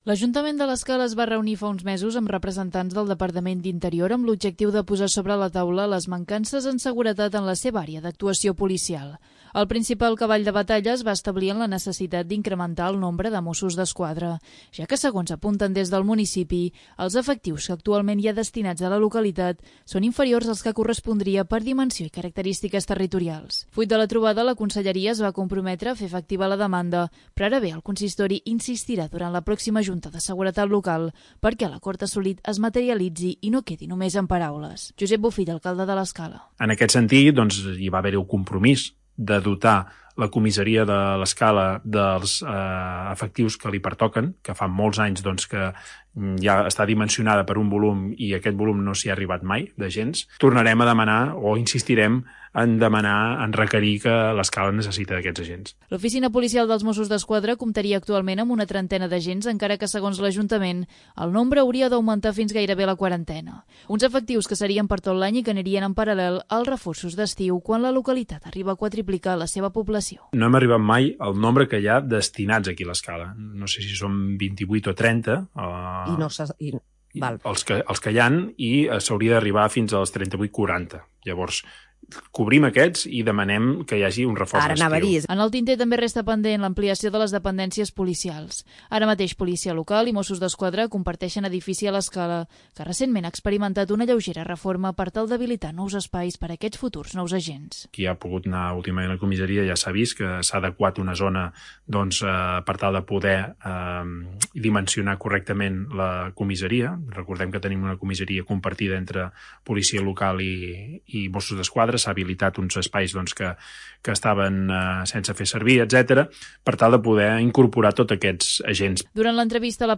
Durant l'entrevista de La Plaça a Ràdio l'Escala, el batlle, Josep Bofill, també ha senyat que la Policia Local té una plantilla de 32 persones, i que durant l'estiu hi haurà un reforç de 4 agents.